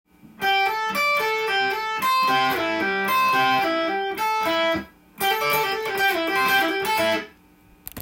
マイナーペンタトニックスケールを使いながら
tab譜のフレーズはAmキーで使用できます。
Amペンタトニックスケールをセーハしながら横移動するという